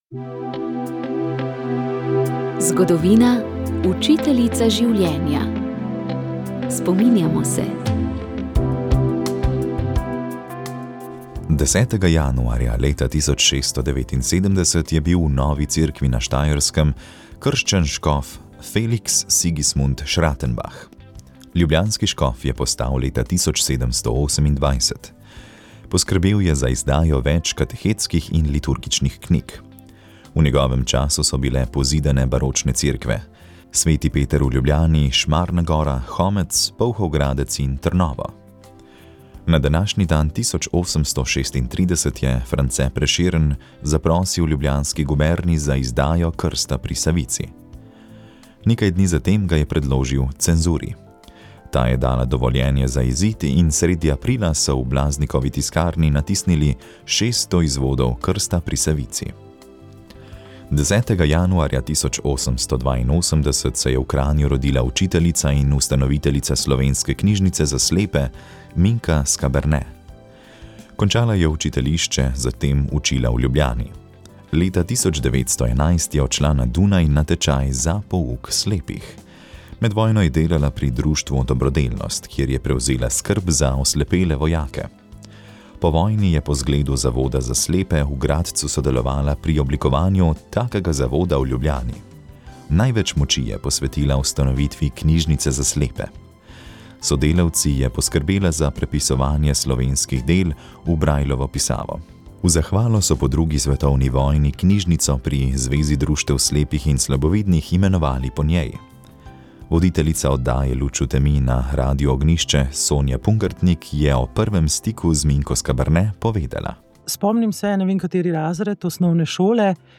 Med mojstrovine skladatelja Josipa Klemenčiča gotovo spada tudi pesem Zemski vrtovi, s katero smo začeli tokratno oddajo, v drugi polovici oddaje pa ste slišali nekaj slovenskih zborovskih zasedb in sveže novice, povezane s skorajšnjim začetkom Festivala Europa Cántat.